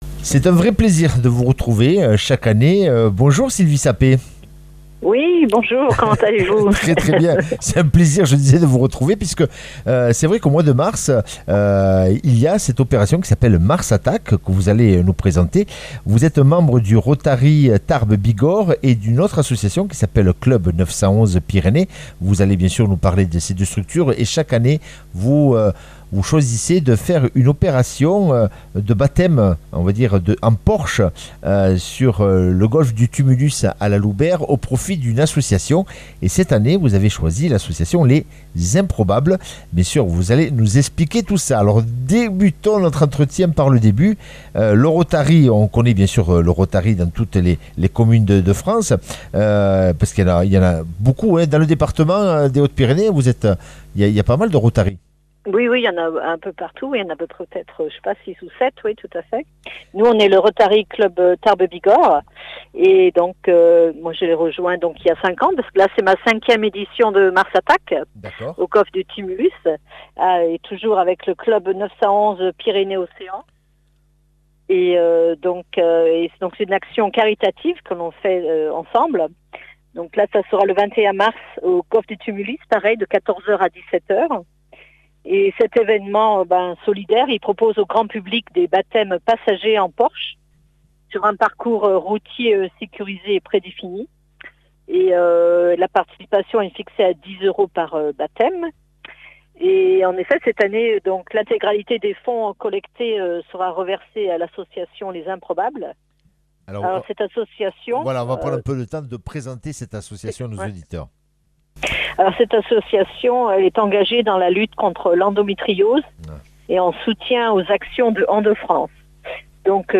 mercredi 18 mars 2026 Interview et reportage Durée 10 min